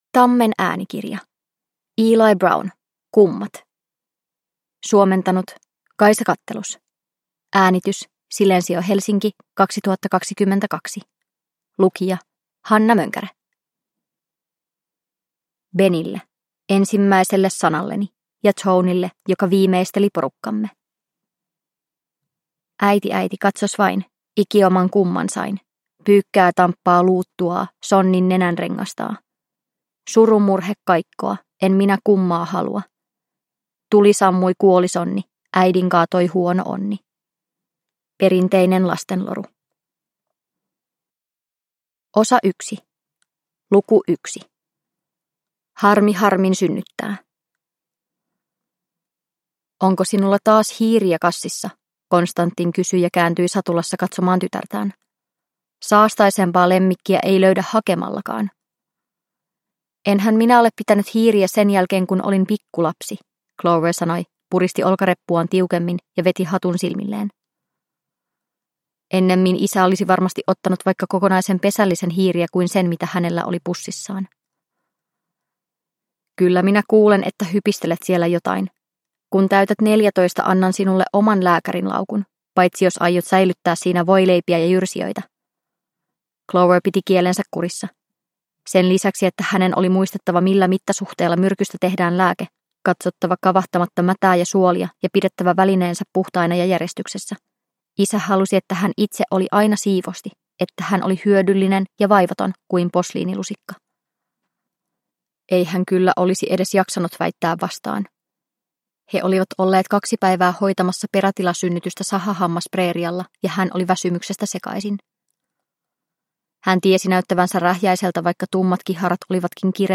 Kummat – Ljudbok – Laddas ner